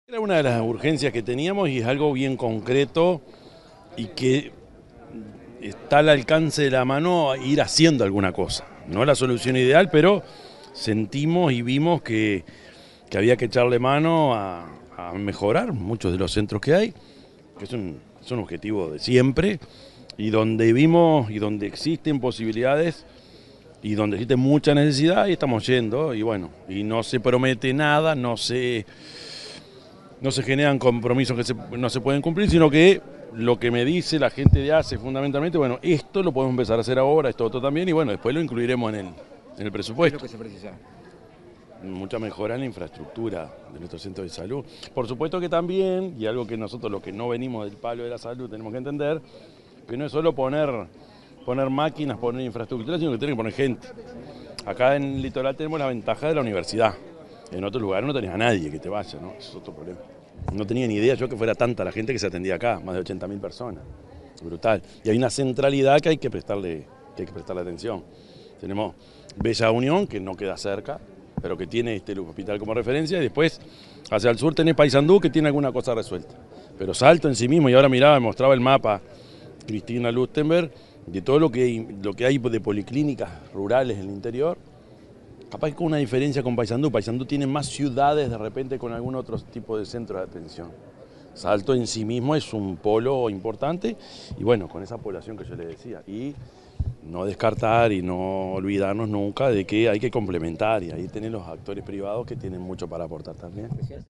Declaraciones del presidente de la República, Yamandú Orsi
Declaraciones del presidente de la República, Yamandú Orsi 08/05/2025 Compartir Facebook X Copiar enlace WhatsApp LinkedIn Este jueves 8, el presidente de la República, profesor Yamandú Orsi, realizó una recorrida por el hospital regional de Salto, donde dialogó con la prensa local.